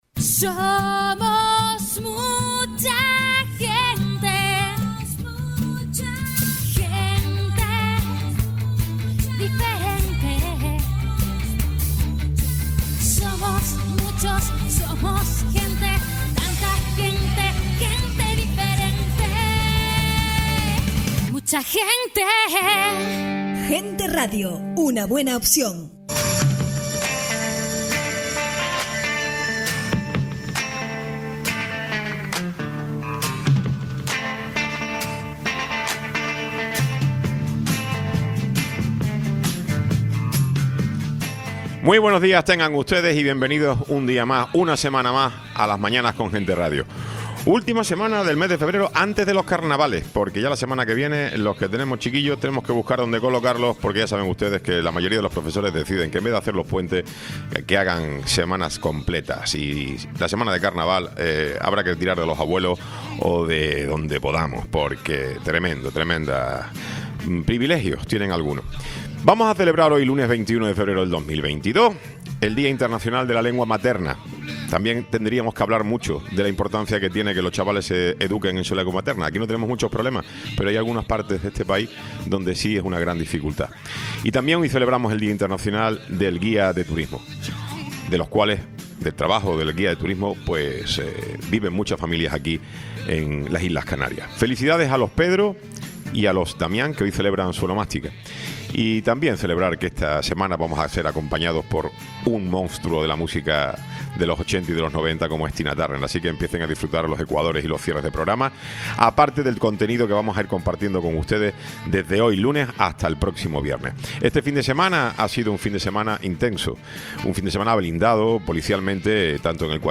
Francis Glez. Alcalde de Icod de los Vinos